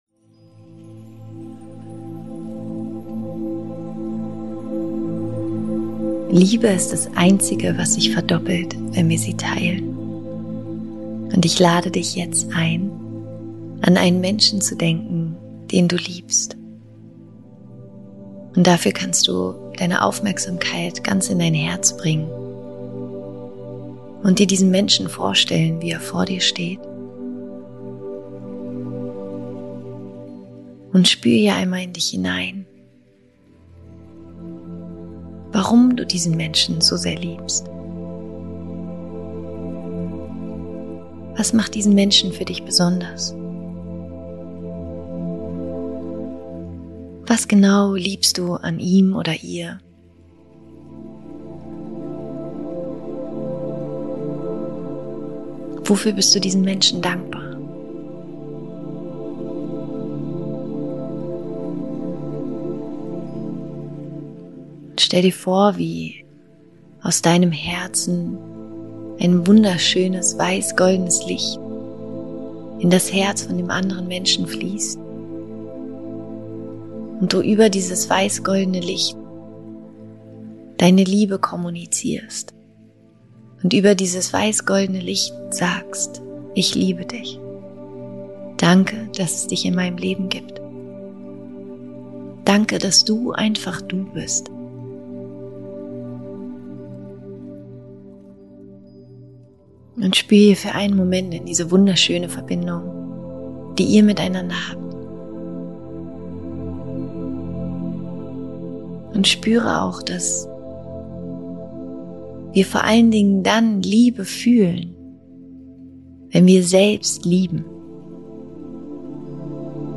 Die Meditation, die ich für dich aufgenommen habe, hilft dir dabei, die Mauern um dein Herz sanft zum Einstürzen zu bringen, damit Liebe wieder frei zu dir fließen kann.